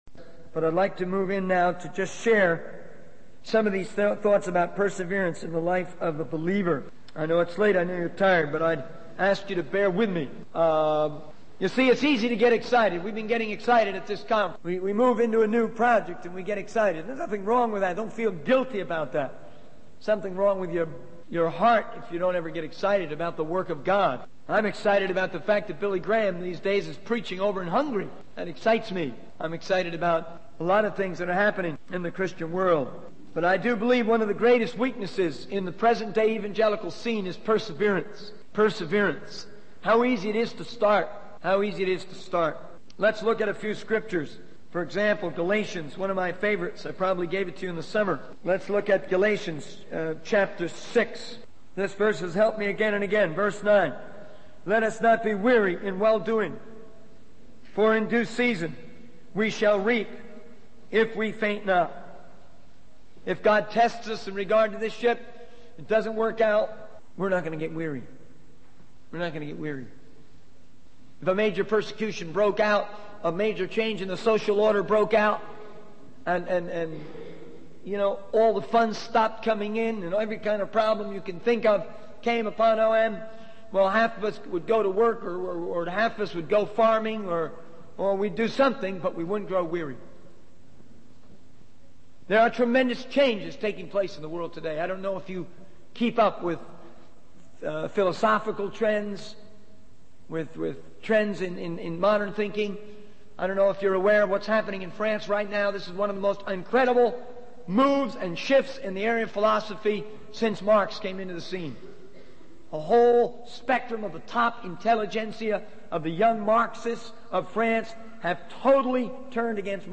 In this sermon, the speaker reflects on various Bible verses that have personally impacted them. They emphasize the importance of not growing weary in doing good and trusting in God's provision. The speaker encourages listeners to persevere in their faith and not be discouraged by challenges or changes in the world.